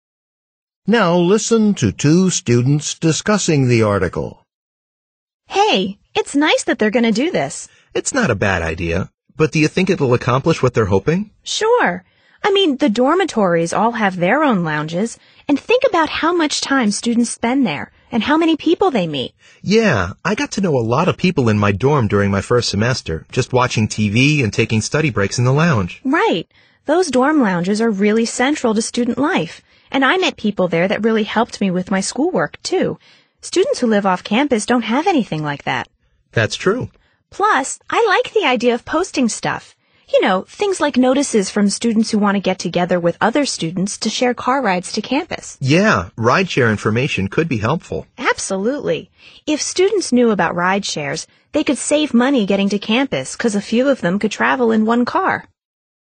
The woman expresses her opinion about the university’s plan.
tpo40_speaking3_question3_dialog.mp3